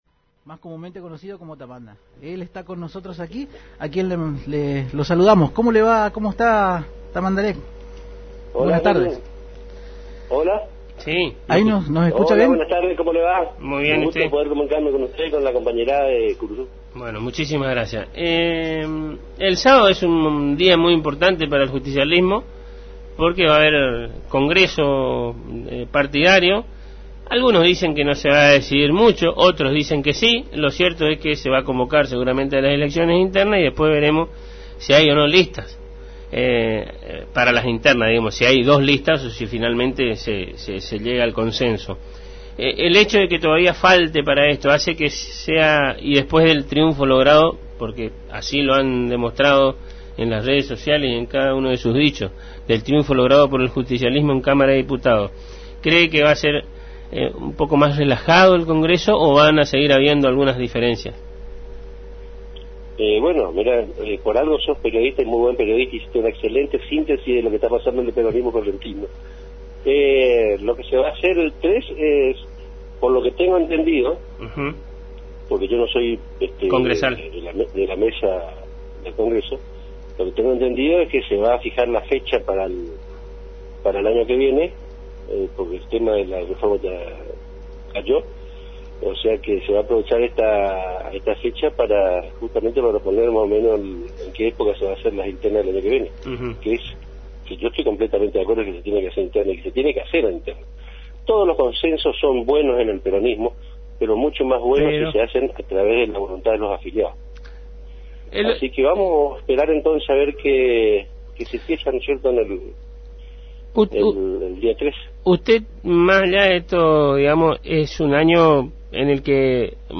(Audio) El presidente del Consejo de Corrientes Capital del PJ, el diputado provincial mandato cumplido, Ramiro Tamandaré Ramírez Forte mantuvo un contacto telefónico con Agenda 970 por la AM 970 Radio Guaraní donde analizó el espectro partidario de cara a su próximo Congreso que se realizará éste sábado que viene.